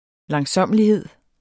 Udtale [ lɑŋˈsʌmˀəliˌheðˀ ]